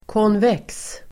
Ladda ner uttalet
konvex adjektiv (om yta), convex [used of surfaces]Uttal: [kånv'ek:s] Böjningar: konvext, konvexaDefinition: som buktar utåtRelaterade ord: konkav (antonym) (concave)